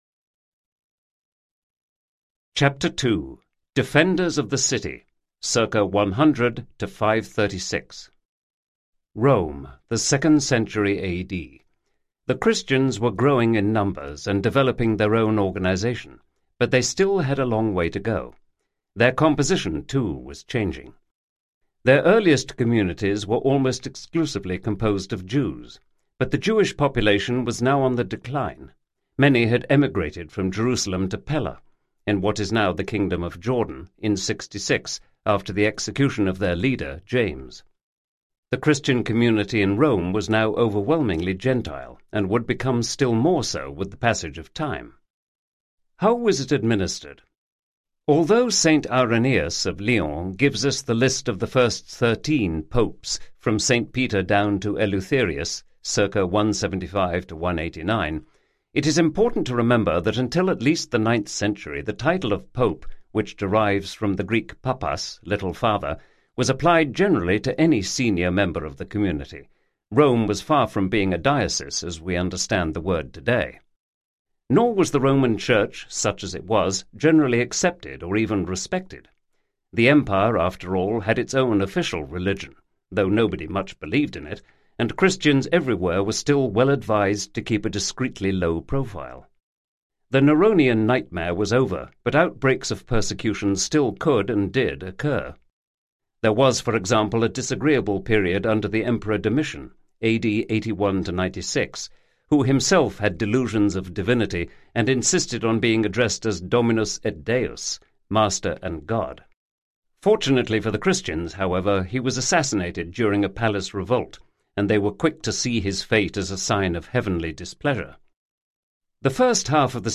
Absolute Monarchs Audiobook